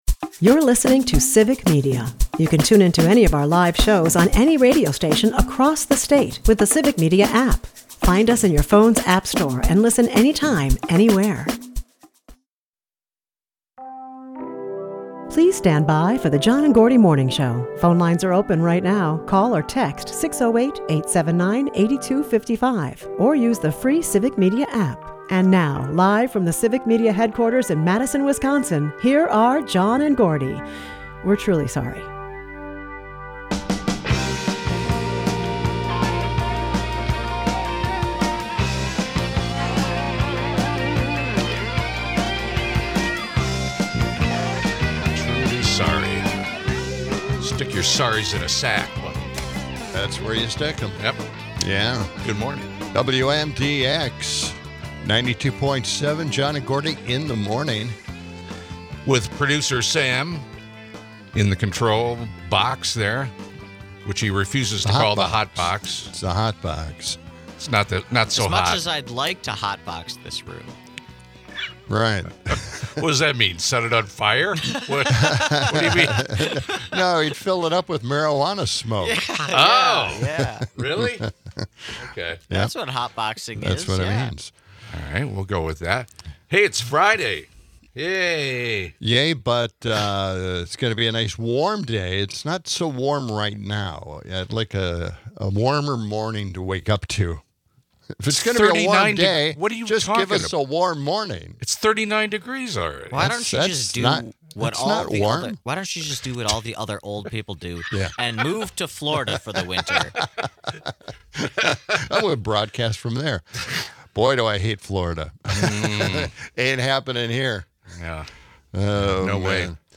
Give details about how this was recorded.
(a cut we played yesterday with some naughty words in it, now taken out.)